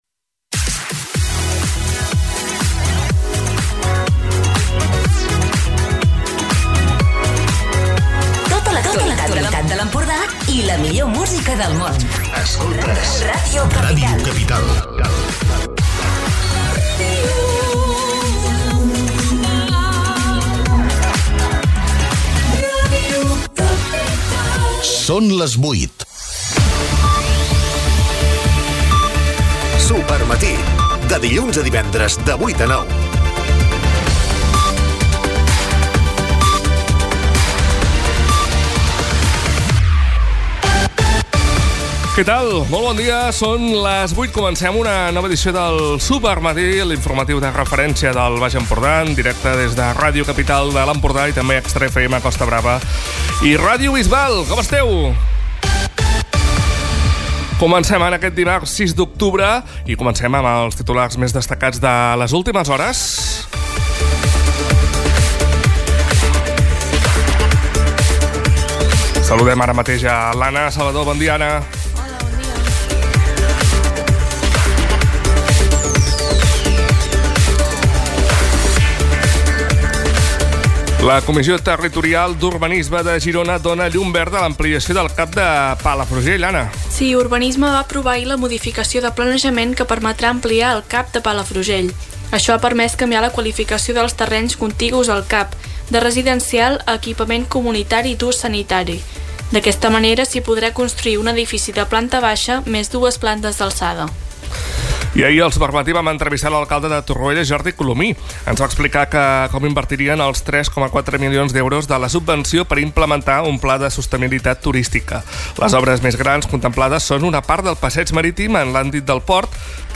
Recupera l'informatiu de referència del Baix Empordà!